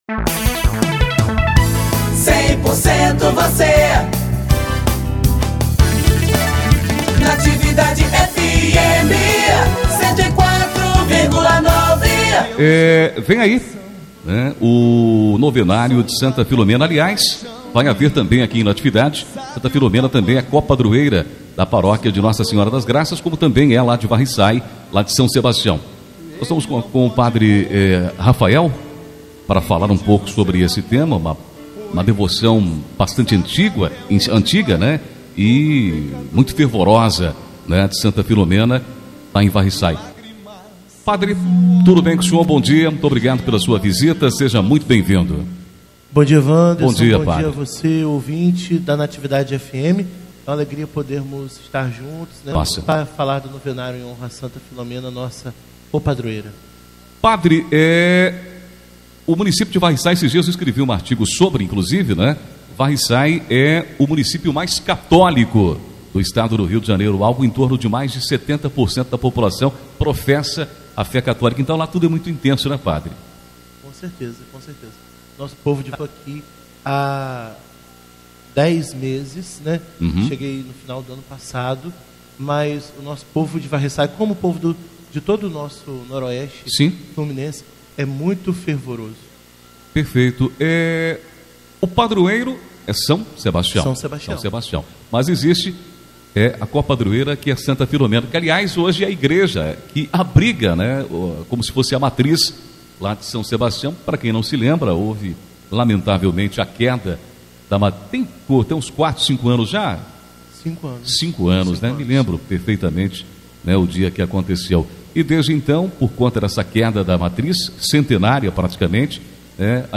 ENTREVISTA-NOVENA.mp3